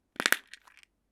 Abrir la tapa de una caja pequeña
Sonidos: Oficina
Sonidos: Hogar